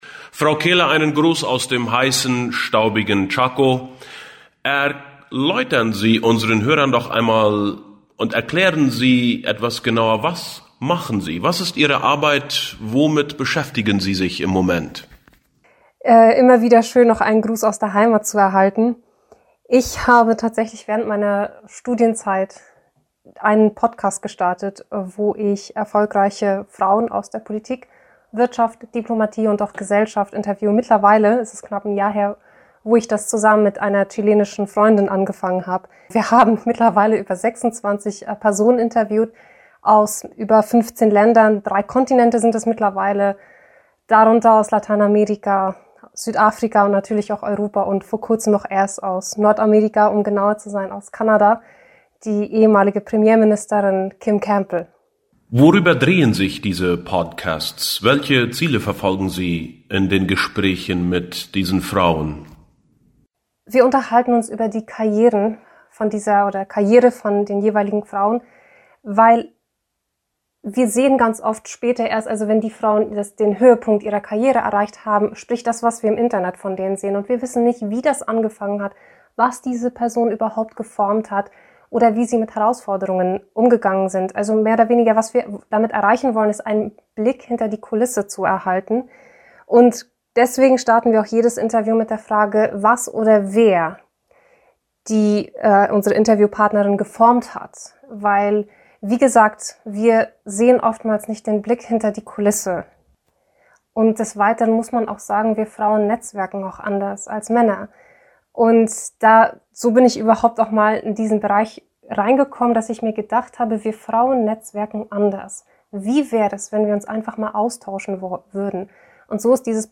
Podcast Interviews